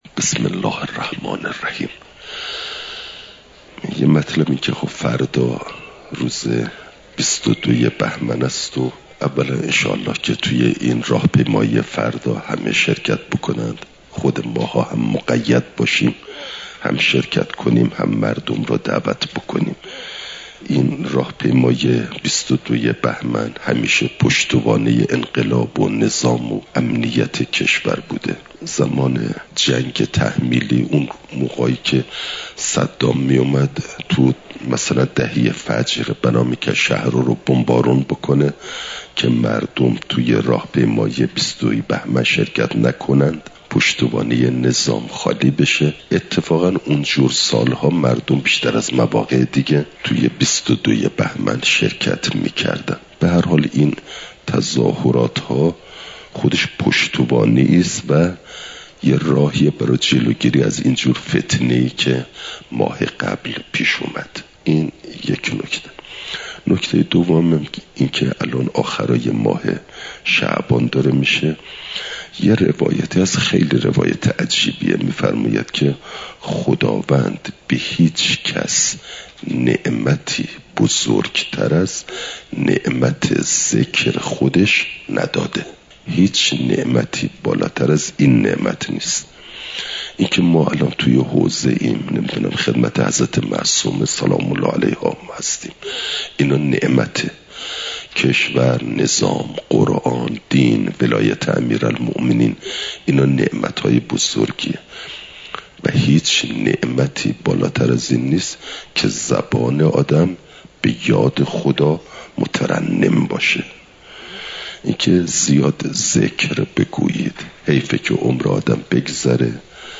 سه شنبه ۲۱ بهمن ماه ۱۴۰۴، حرم مطهر حضرت معصومه سلام ﷲ علیها